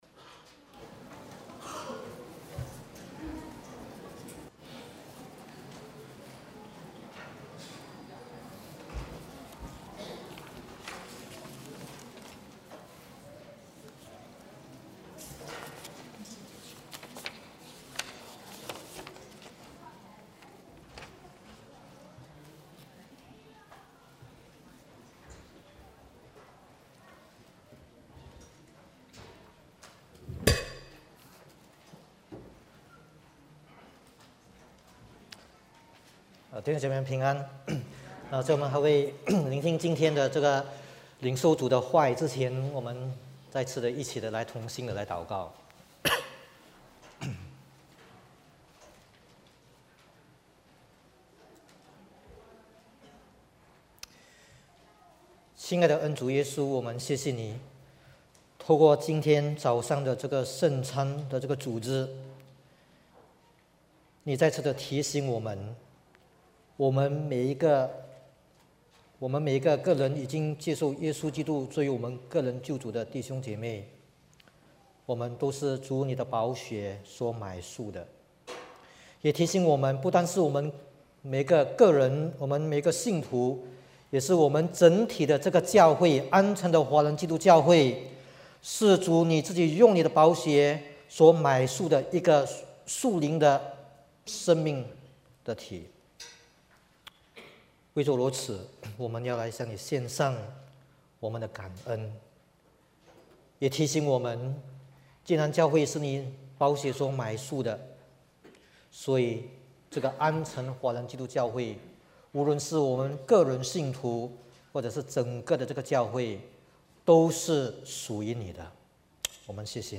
Mandarin Sermons Home / Mandarin Sermons